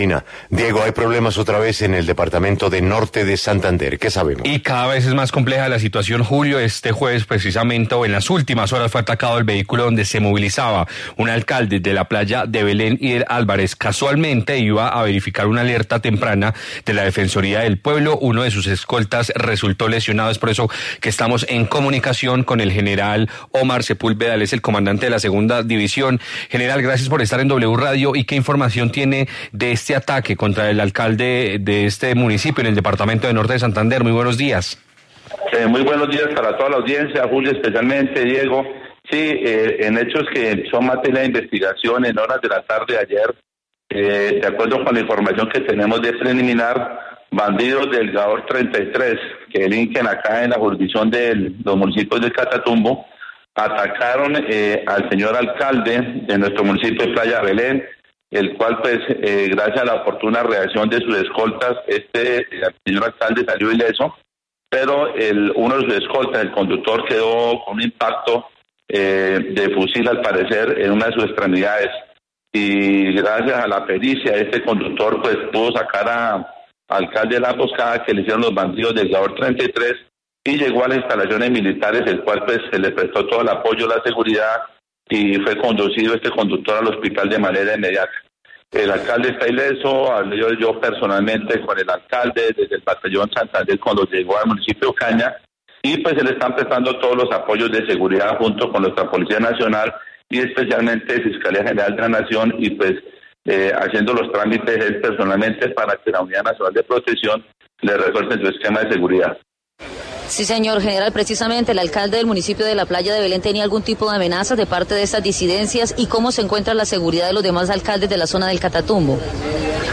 En diálogo con La W, el mayor general Ómar Esteban Sepúlveda Carvajal, comandante de la Segunda División del Ejército Nacional, brindó detalles sobre los hechos.